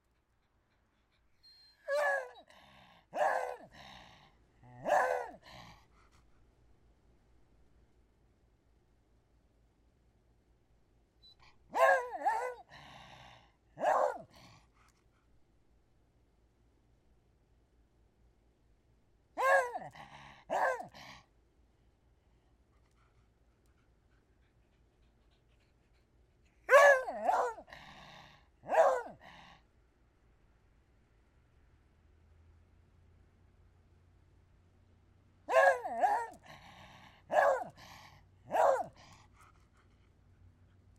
老小猎犬吠叫和抱怨
Tag: 树皮 小猎犬